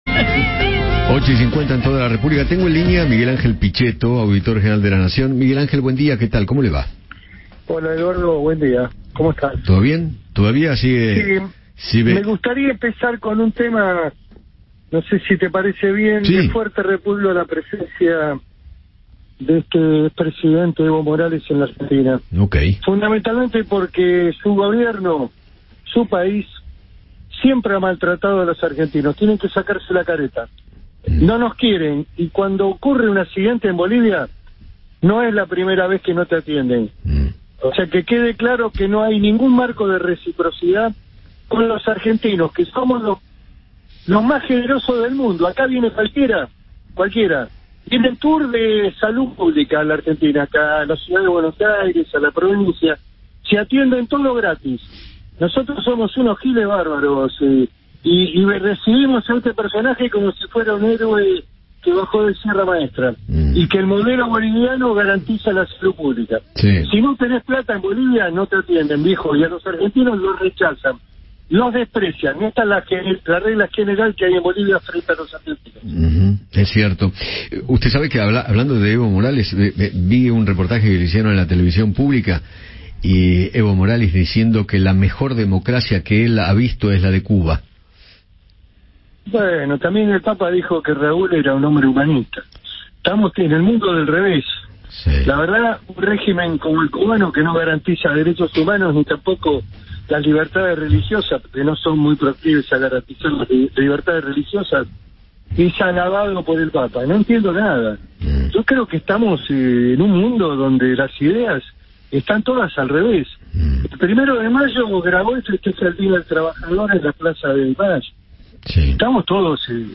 Miguel Ángel Pichetto, Auditor General de la Nación, charló con Eduardo Feinmann sobre los anuncios de la nueva ministra de Economía y repudió la visita de Evo Morales al país.